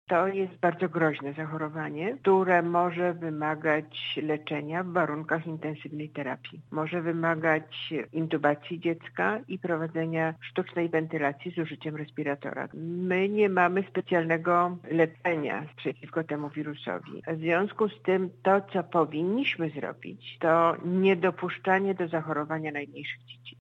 – mówi prof. Ewa Helwich, Konsultant Krajowy w dziedzinie neonatologii.